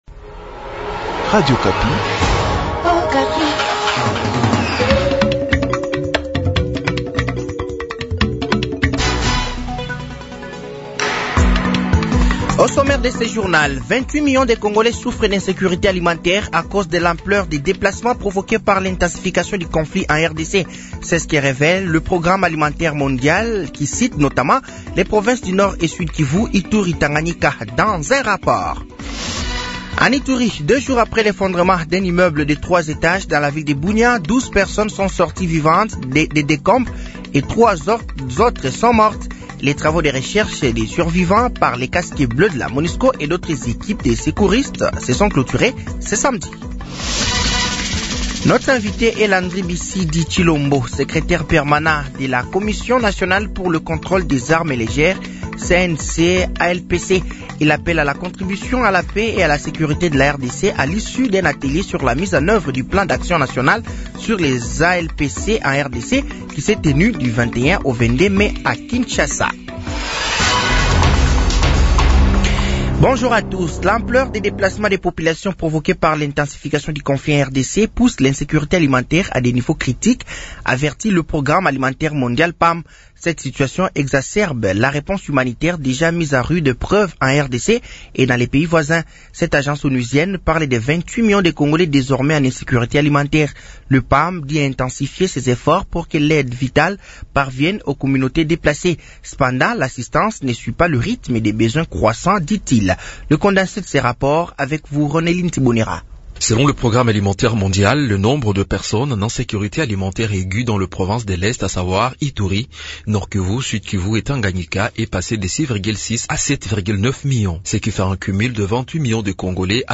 Journal français de 15h de ce samedi 24 mai 2025